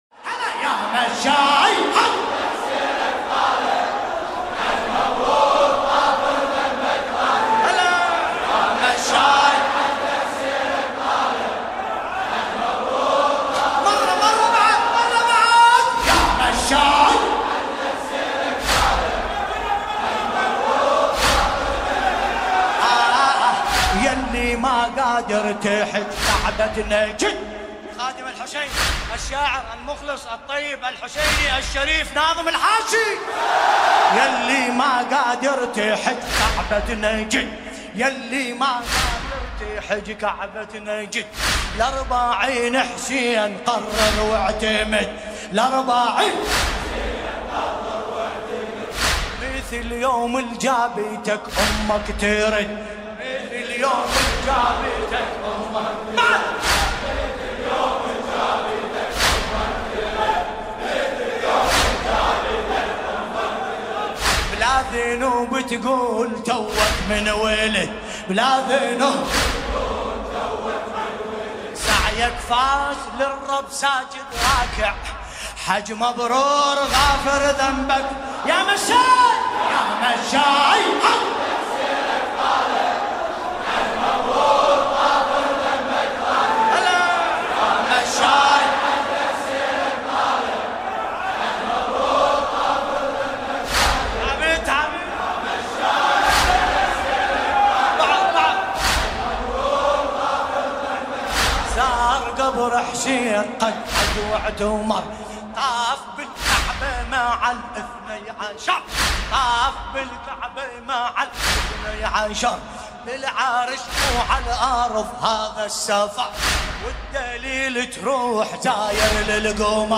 المناسبة : ليلة 20 محرم 1441 هـ
طور : يا_ستارحسينية الحاج داوود العاشور البصرة